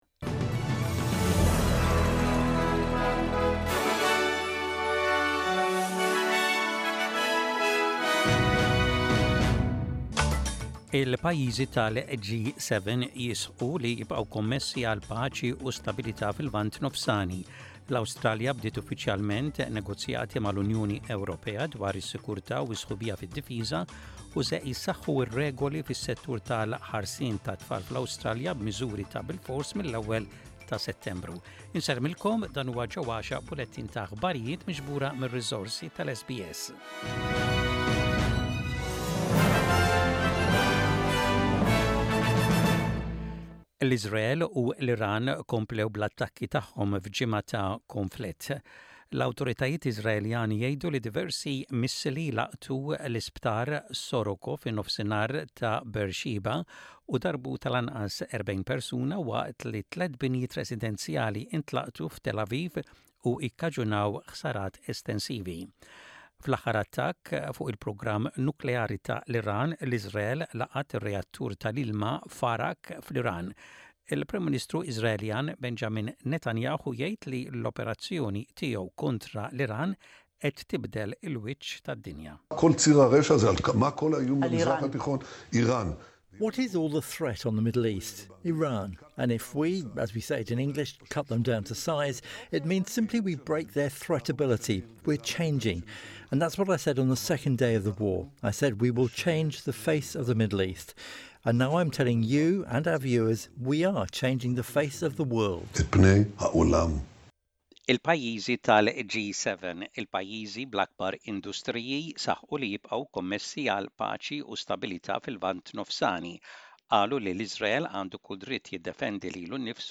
Aħbarijiet bil-Malti: 20.06.25